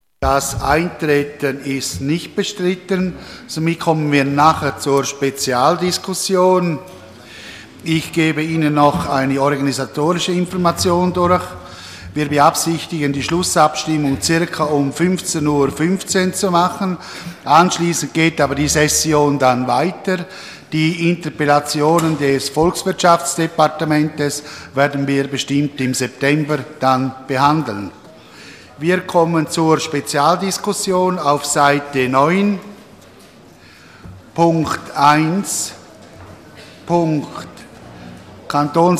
Session des Kantonsrates vom 2. bis 4. Juni 2014